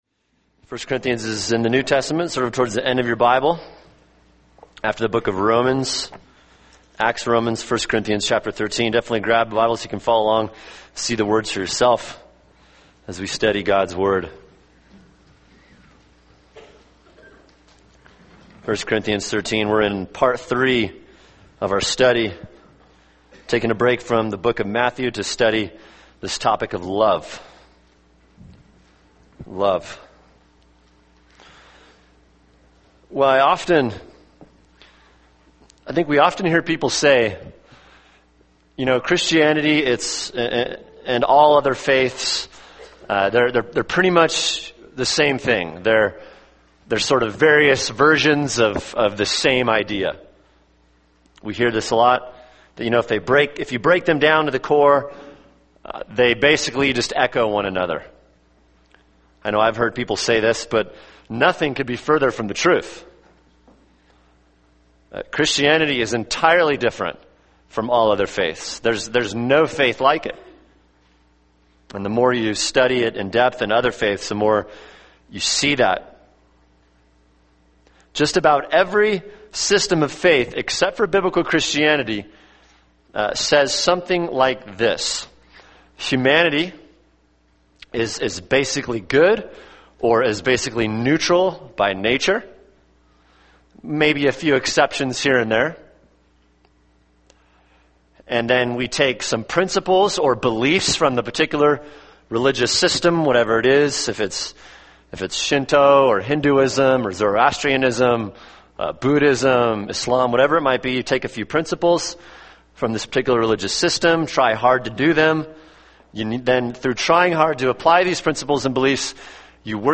[sermon] 1 Corinthians 13:1-7 – Love (part 3) | Cornerstone Church - Jackson Hole